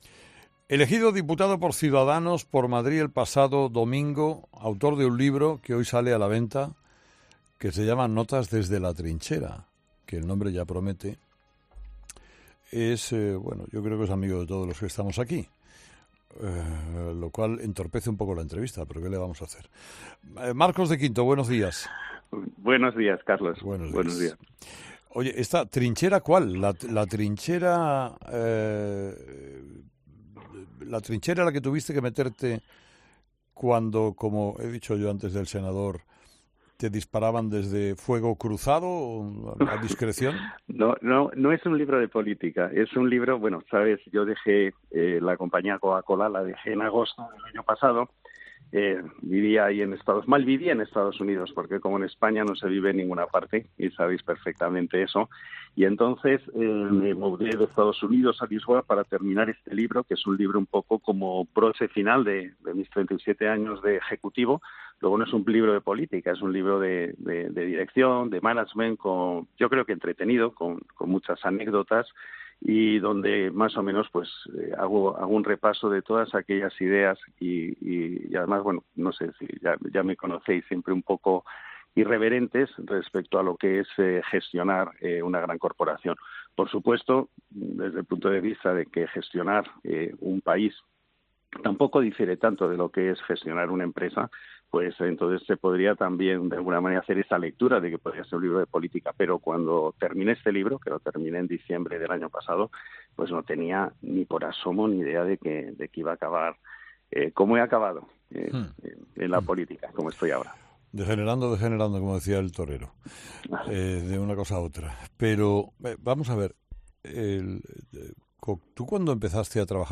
Marcos de Quinto, nuevo diputado de Ciudadanos en el Congreso , ha participado en 'Herrera en COPE' para hablar de su nuevo libro "Notas desde la trinchera".